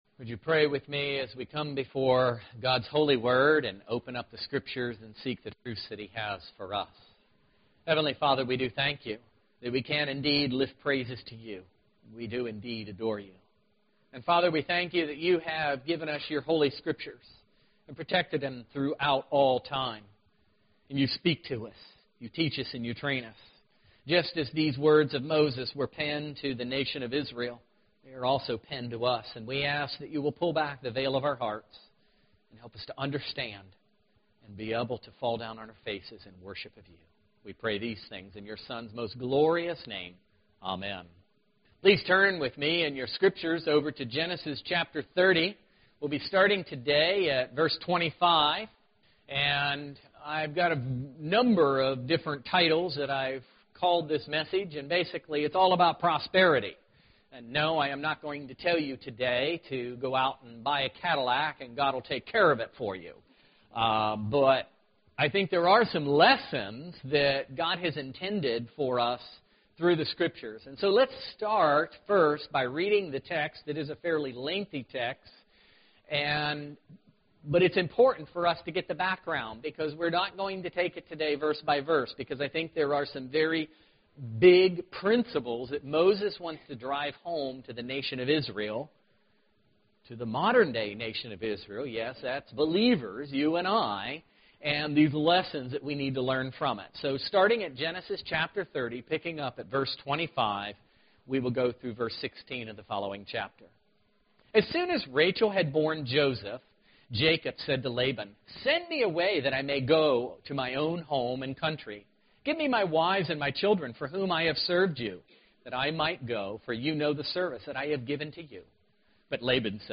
Romans 8:32 Service Type: Sunday Morning Worship